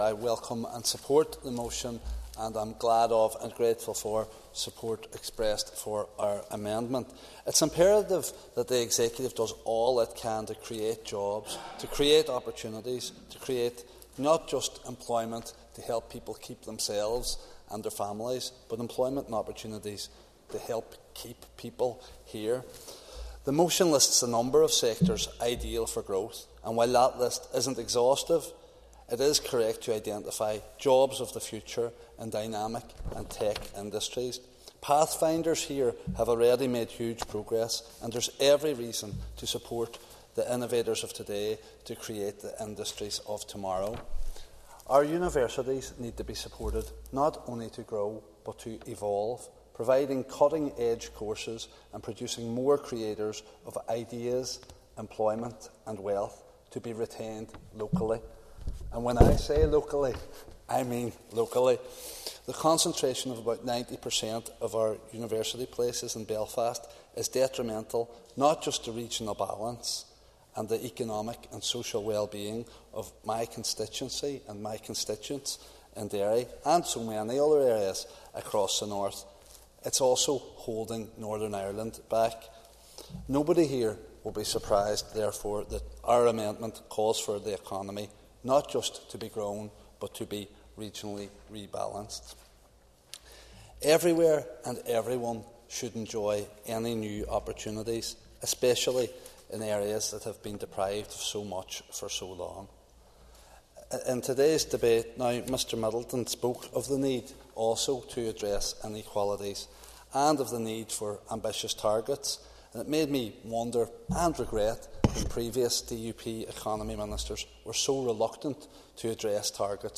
Mark Durkan was speaking during a debate on job creation targets.